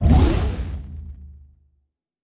pgs/Assets/Audio/Sci-Fi Sounds/Weapons/Sci Fi Explosion 13.wav at master
Sci Fi Explosion 13.wav